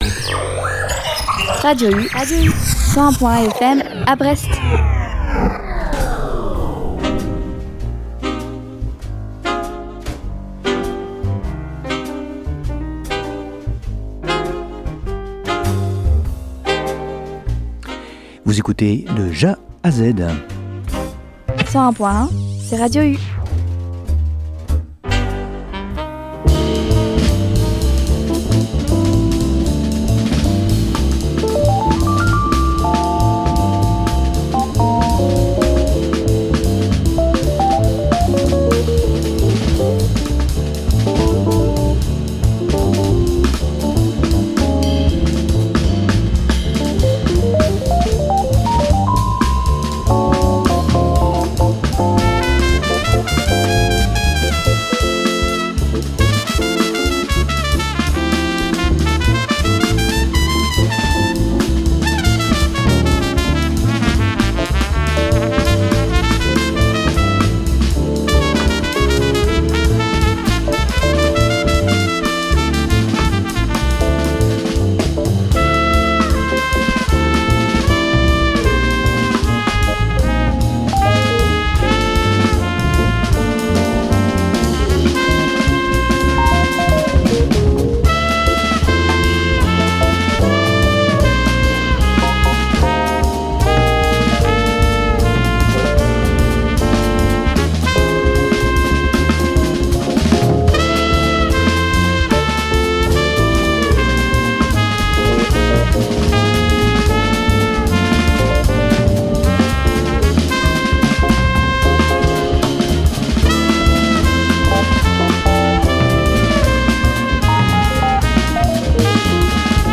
Retrouvez ma sélection jazz du mois